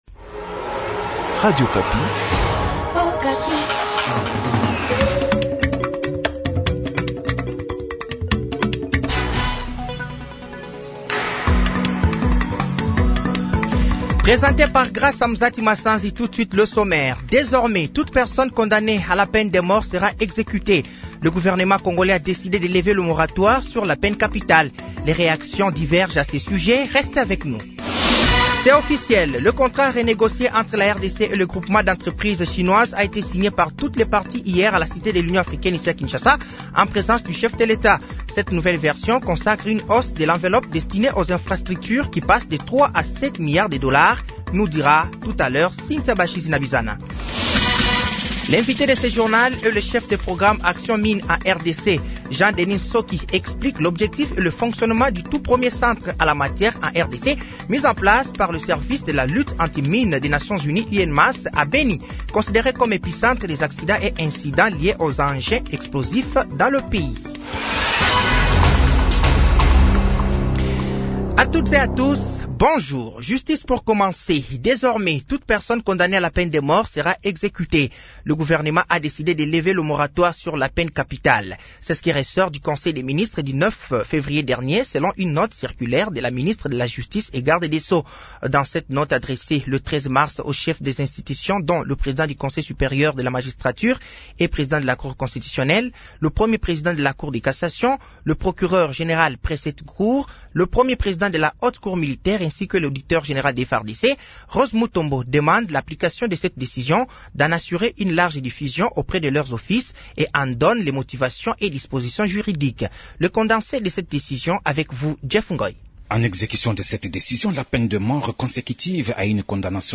Journal midi
Journal français de 12h de ce vendredi 15 mars 2024